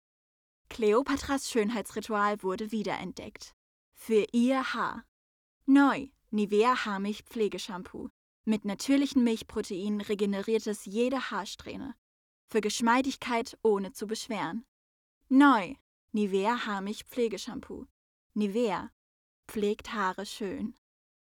Deutsch-russische Sprecherin mit Gesangserfahrung, ehemalige Solistin im jungen Ensemble des Friedrichstadt Palast Berlin; Schubert Schauspielmanagement
Kein Dialekt
Sprechprobe: Werbung (Muttersprache):